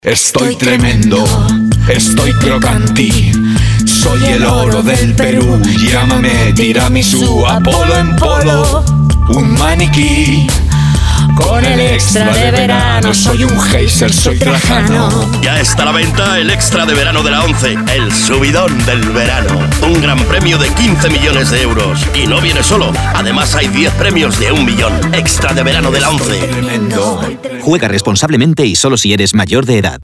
Cuña Extra de Verano de la ONCE (Trajano) formato MP3 audio(1,15 MB)